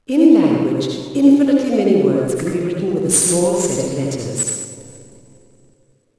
3. Galmveld.
spraak in een lege concertzaal.
Dit fragment werd achteraf bewerkt om het geluidveld in de omgeving van een reflecterende wand en een galmveld te simuleren.
Speech_hall2.wav